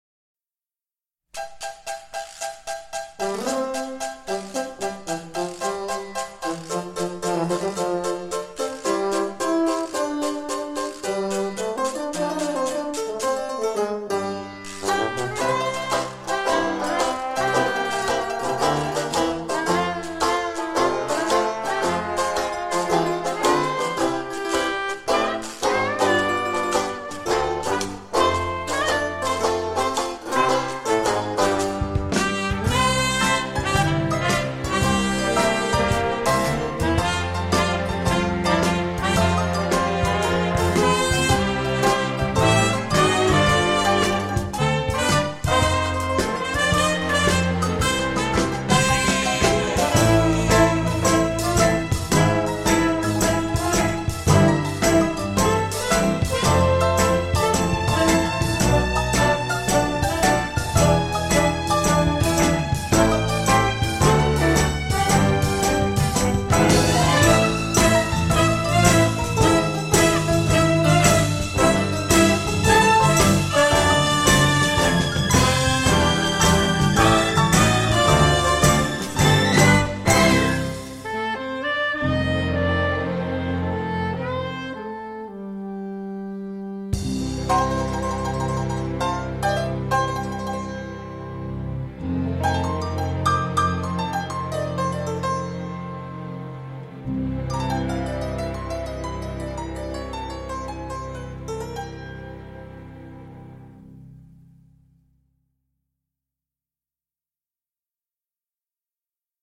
Groovy, jazzy, romantique, classique, pêchu et varié.